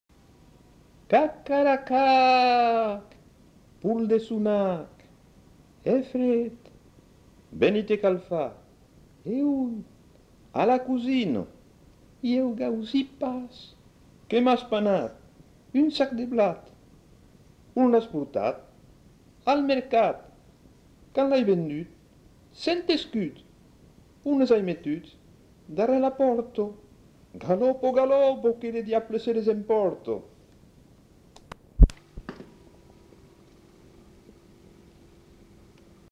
Formulette enfantine
Genre : forme brève
Effectif : 1
Type de voix : voix d'homme
Production du son : récité